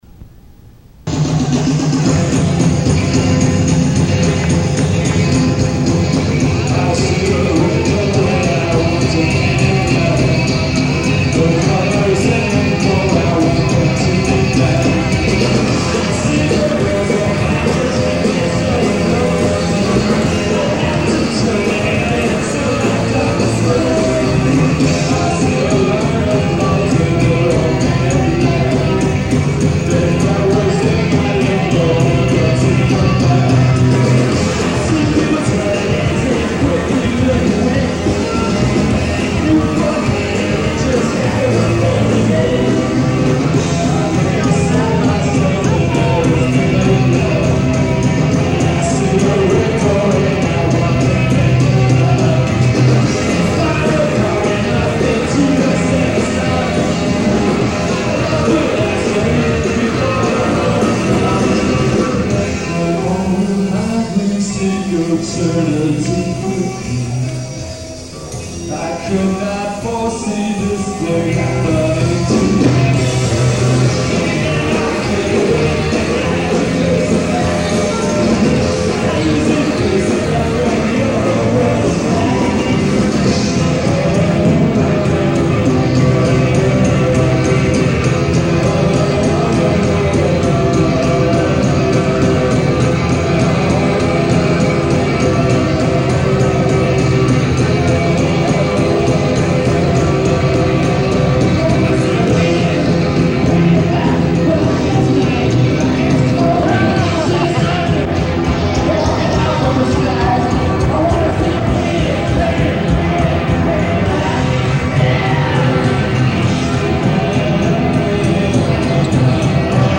rock band